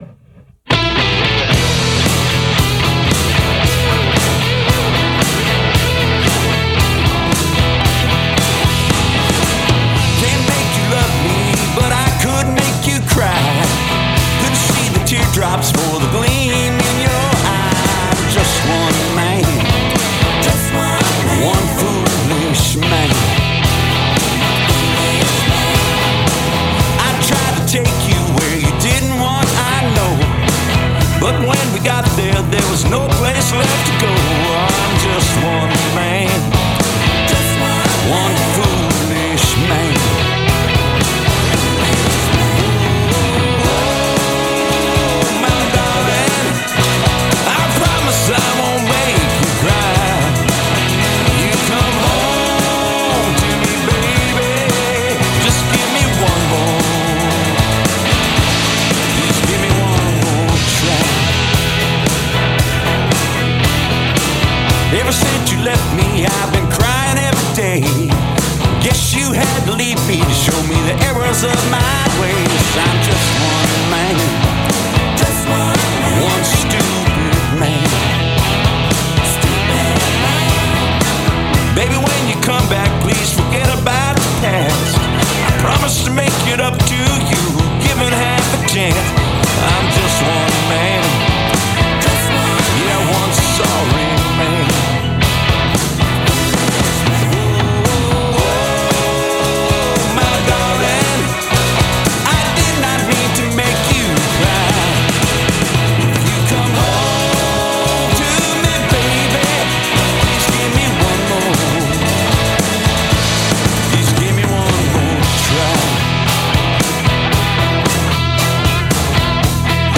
Even running it through AAMS might have been overkill So I did a quick master for comparison (it's also my schtick ... I was dissatisfied with the auto mastering stuff, but couldn't afford a "real" mastering engineer, so I spent 8 years learning how to do it myself).
Since there is some grit in the original, I stuck with as clean a sound as I could. My goal was to breath some life into the track without changing it too much since I thought the mix was pretty good to begin with.